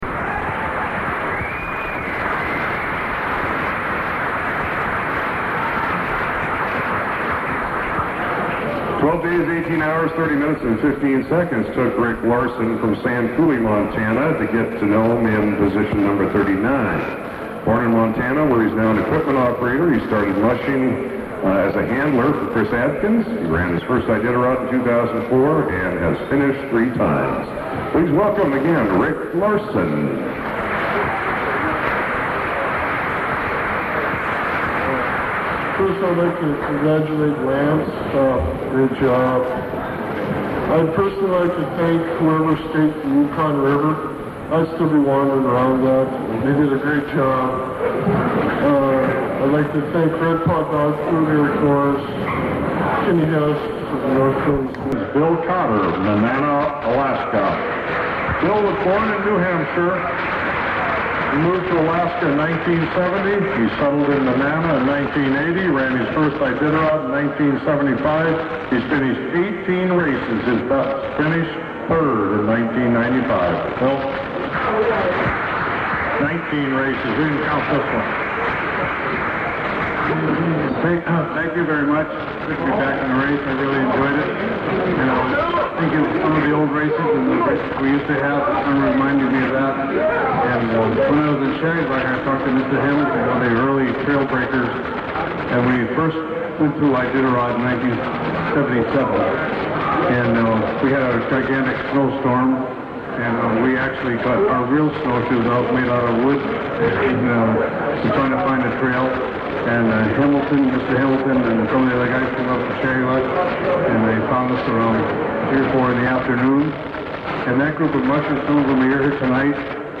Audio of Iditarod Finishers Banquet
IditarodBanquetPart1.mp3